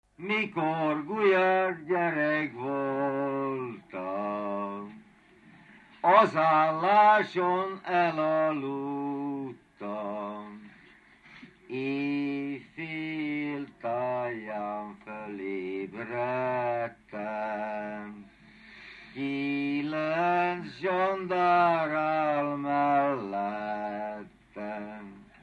Dunántúl - Zala vm. - Csörnyeföld
ének
Stílus: 6. Duda-kanász mulattató stílus
Szótagszám: 8.8.8.8